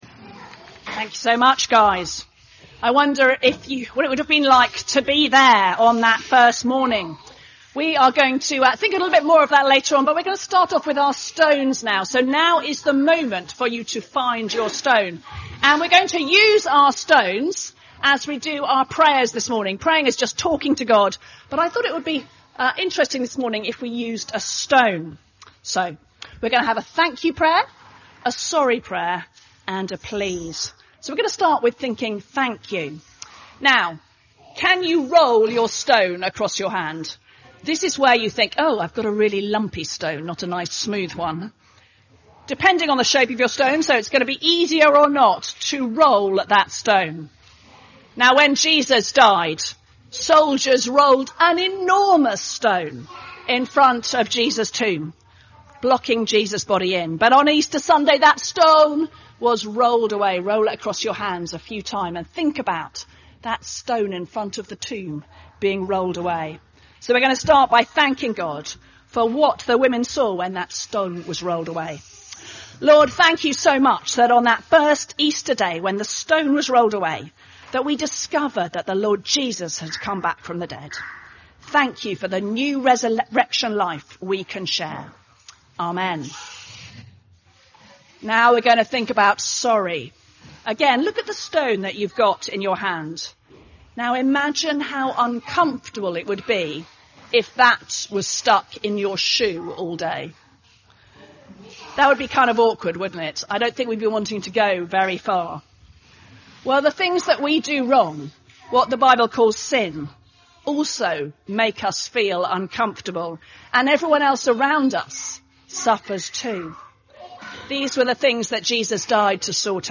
The reading (Matthew 28:1-10) is not on this recording.
Sermons